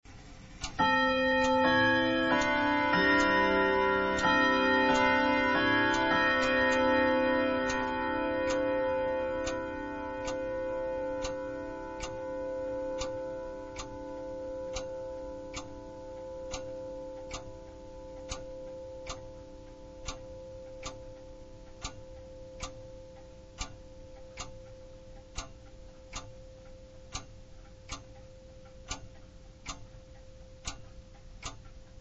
さらに真ちゅうの振り子や　チャイムも磨き上げて修理を完了しました。
１５分おきに色々なチャイムを響かせます。
30分チャイム　モノラル　64ビット　126KB